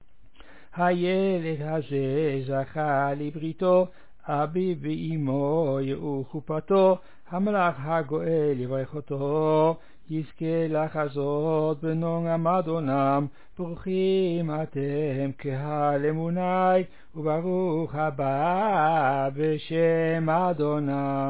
All sing (the last phrase)
reconstructed melody [3]